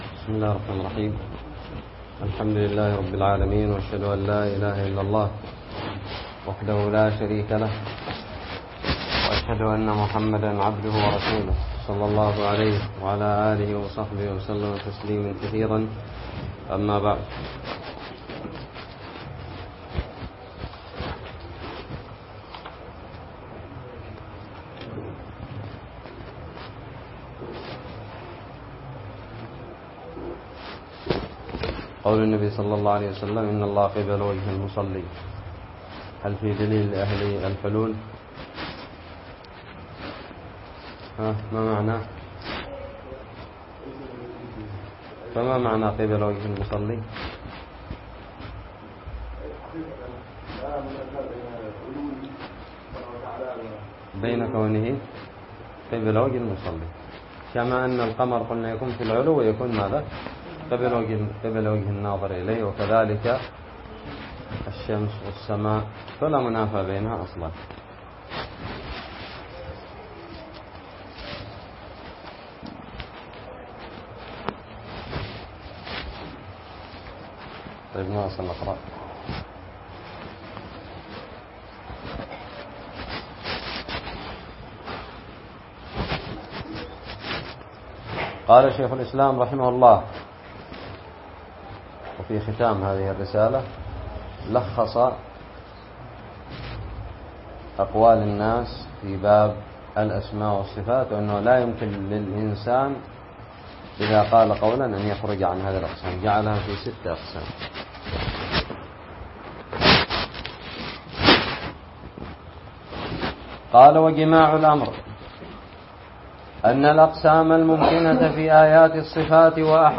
الدرس الرابع والعشرون والأخيرمن شرح متن الحموية
ألقيت في دار الحديث السلفية للعلوم الشرعية بالضالع